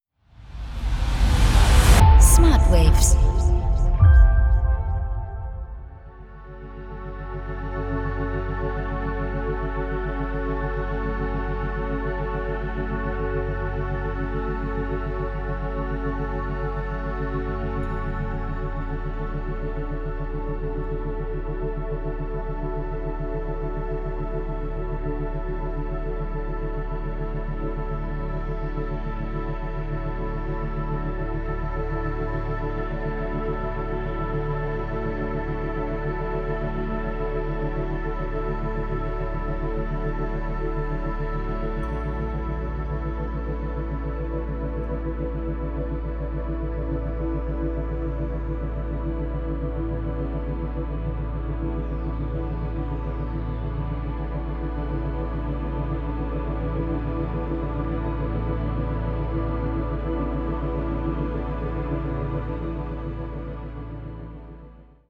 tiefe und entspannende Hintergrundmusik
• Methode: Binaurale Beats
• Frequenz: 5,5-7 Hertz